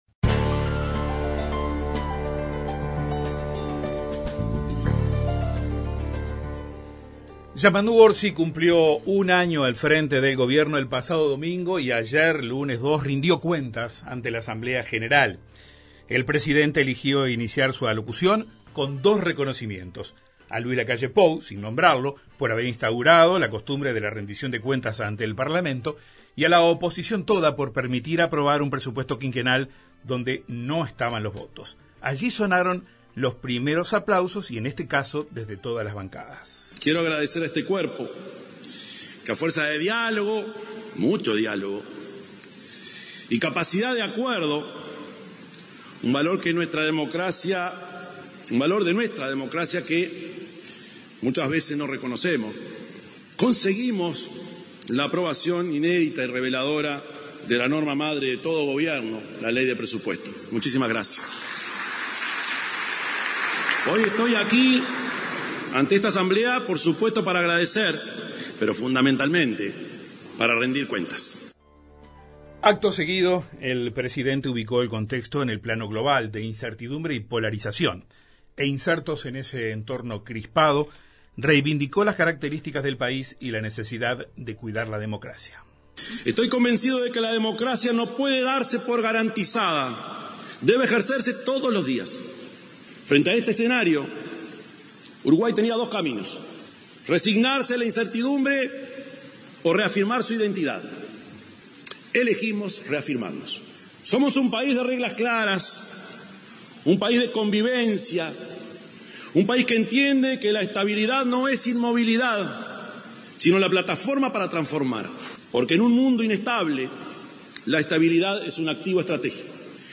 Crónica: el Presidente habló ante la Asamblea General a un año de su mandato